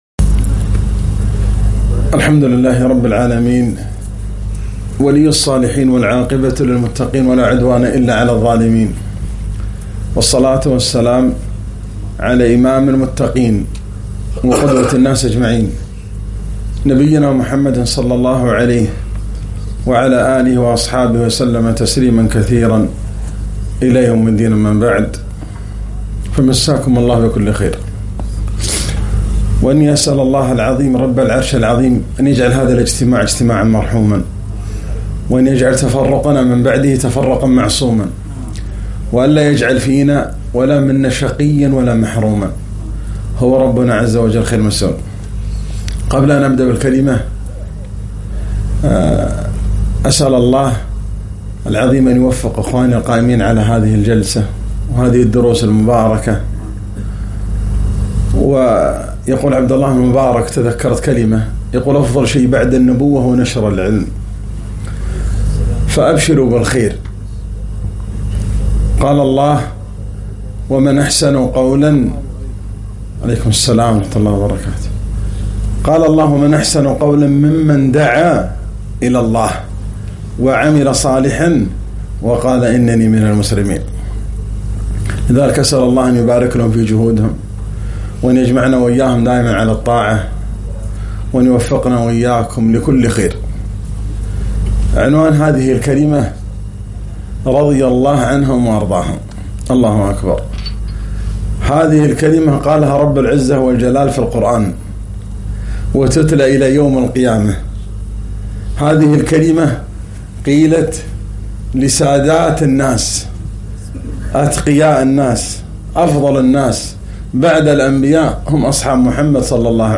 محاضرة مؤثرة عن الأنصار بعنوان ( رضي الله عنهم ورضوا عنه )